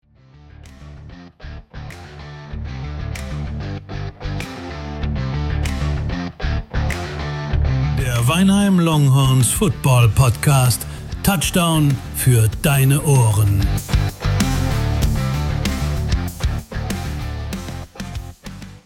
Zwei Typen, ein Team, jede Menge Football.